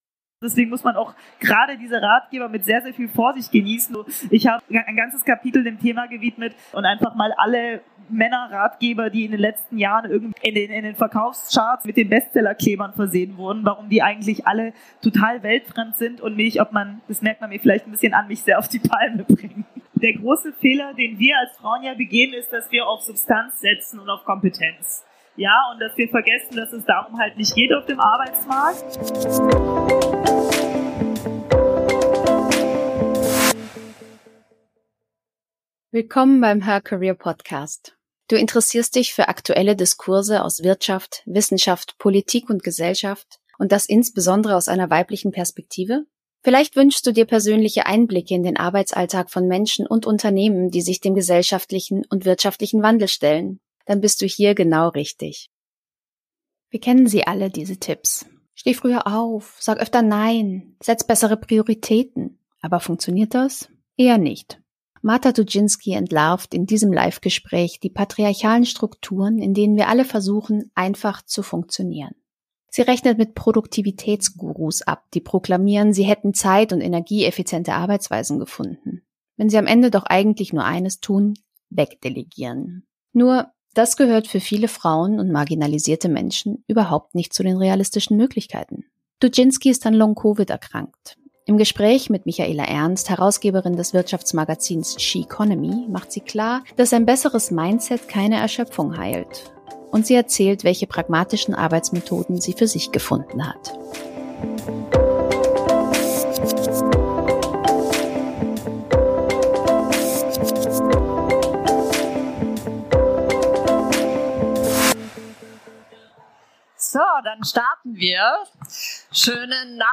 in diesem Live-Gespräch